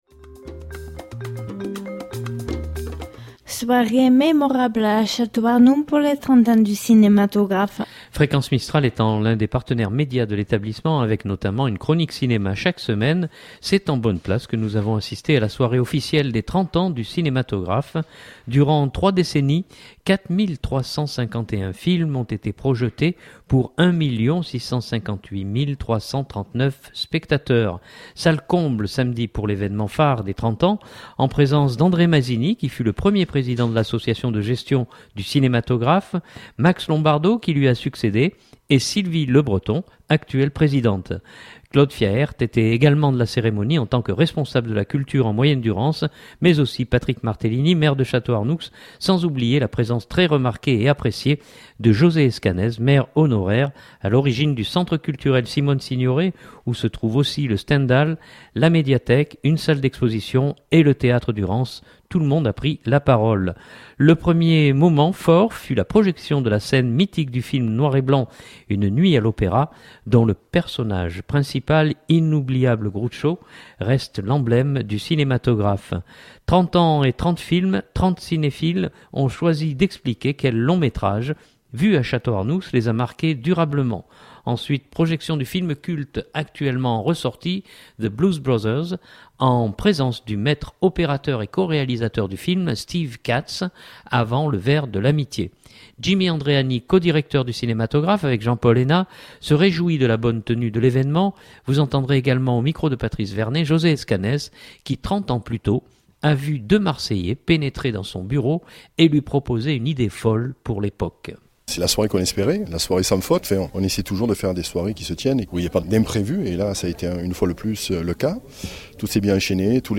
Soirée mémorable à Château-Arnoux pour les 30 ans du Cinématographe !